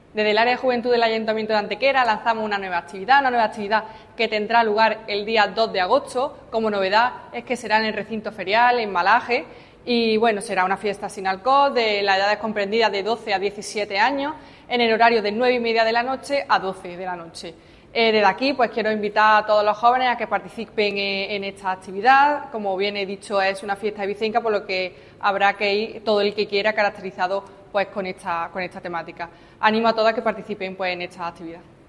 La concejal delegada de Juventud, Marta González, informa del desarrollo de una nueva actividad de ocio destinado para los jóvenes, concretamente para los que tienen edades comprendidas entre los 12 y los 17 años.
Cortes de voz